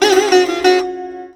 SITAR GRV 10.wav